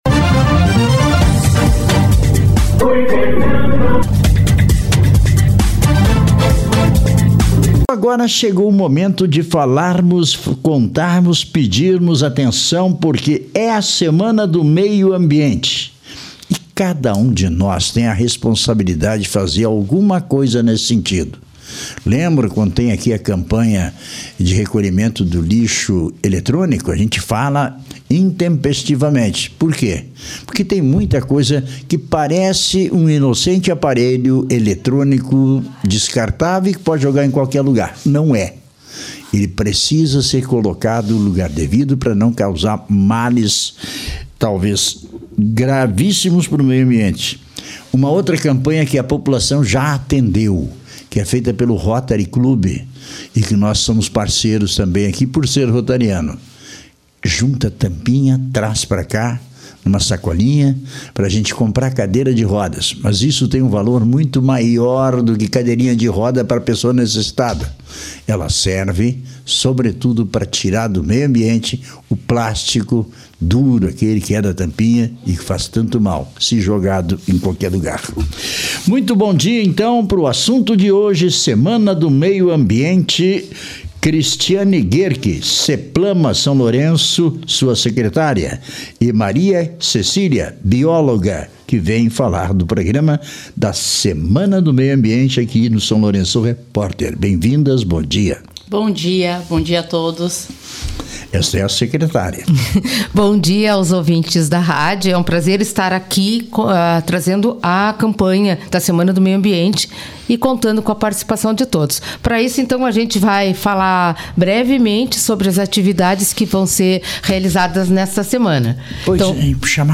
Entrevista com a SEPLAMA